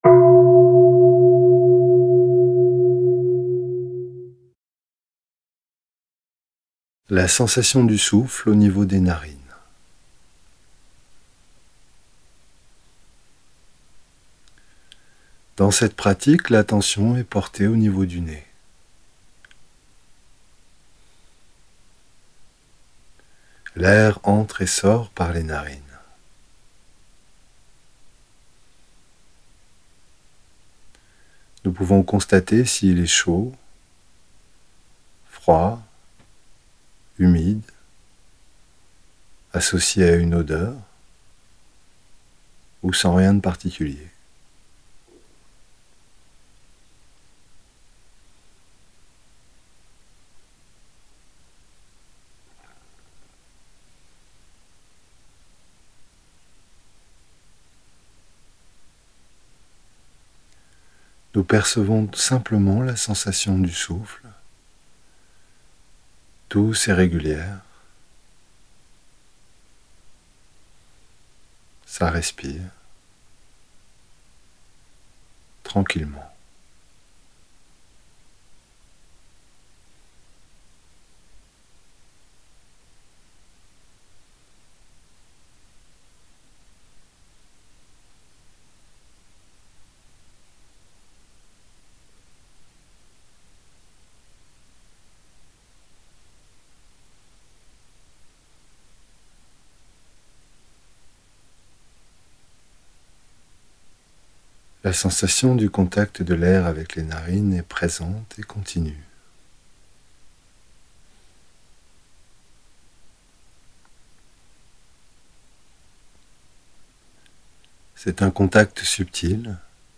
5.ETAPE-2-AUDIO-6-P14-HOMME.mp3